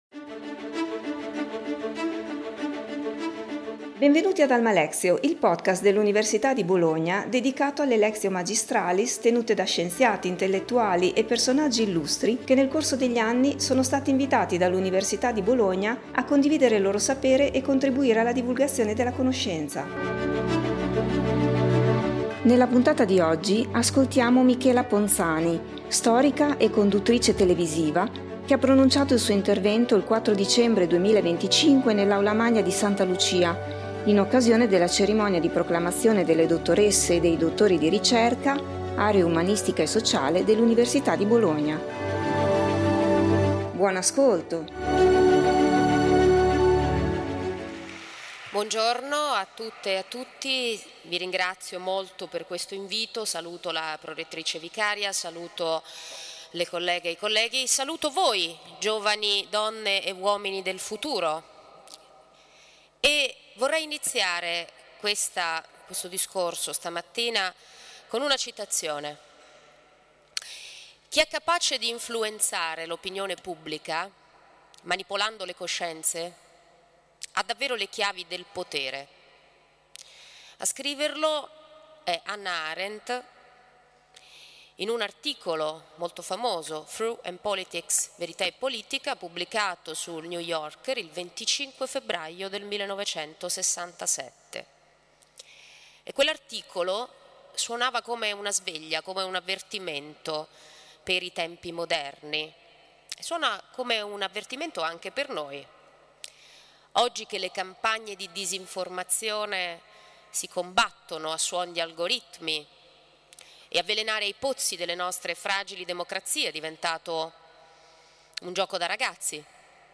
Michela Ponzani, storica e conduttrice televisiva, ha pronunciato il suo intervento il 4 dicembre 2025 nell’Aula Magna di Santa Lucia in occasione della Cerimonia di proclamazione delle dottoresse e dei dottori di ricerca - aree umanistica e sociale dell’Università di Bologna.